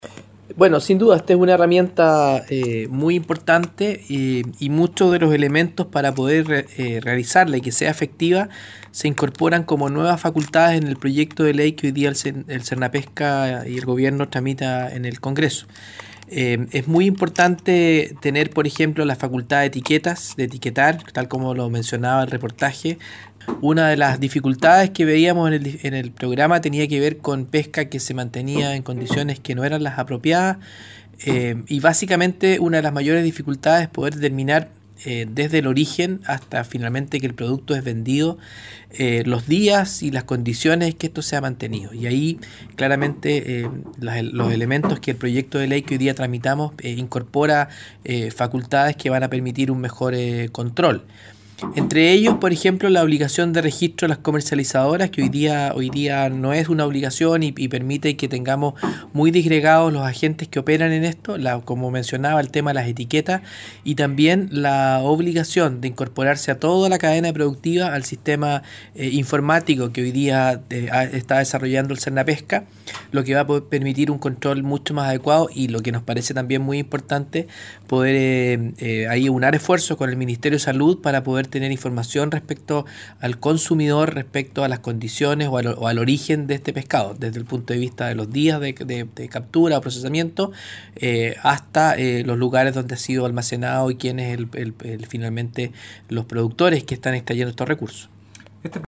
José Miguel Burgos, director nacional del Servicio Nacional de Peca